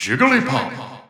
The announcer saying Jigglypuff's name in English releases of Super Smash Bros. 4 and Super Smash Bros. Ultimate.
Category:Jigglypuff (SSB4) Category:Jigglypuff (SSBU) Category:Announcer calls (SSB4) Category:Announcer calls (SSBU)
Jigglypuff_English_Announcer_SSB4-SSBU.wav